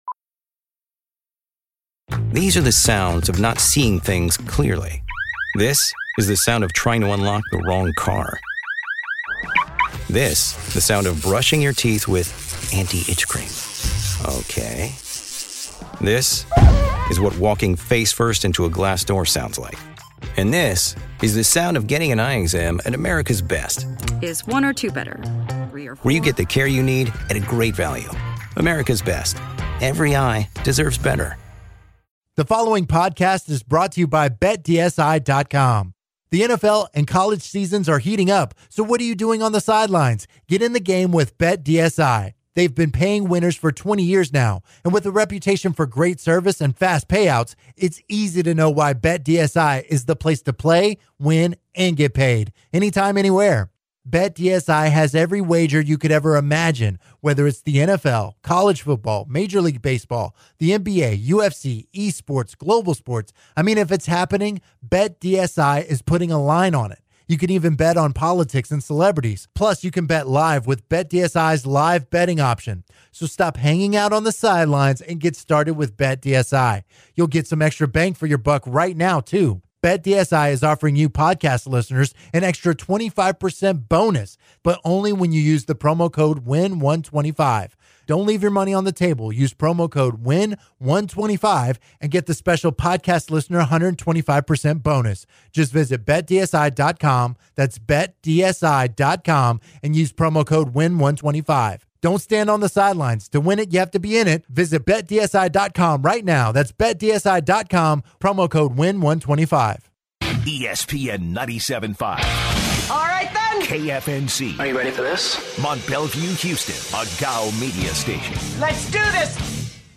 They end the hour with a slew of calls about the Rockets woes.